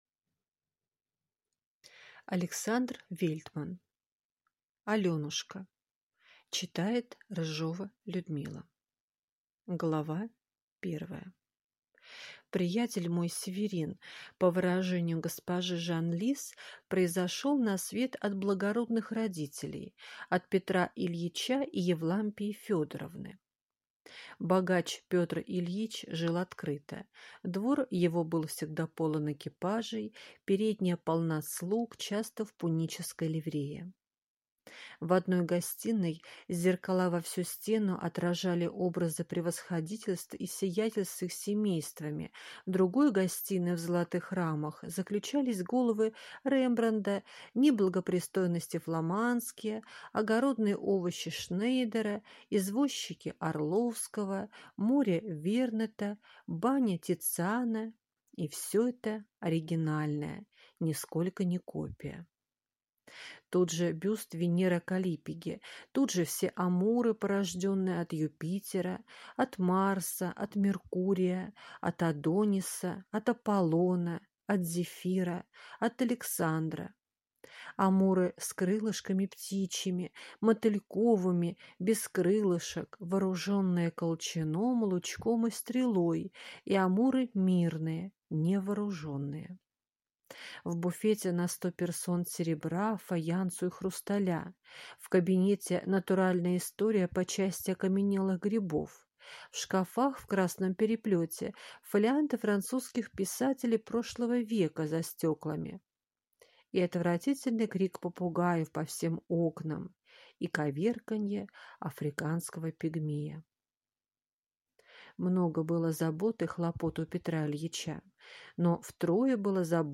Аудиокнига Аленушка | Библиотека аудиокниг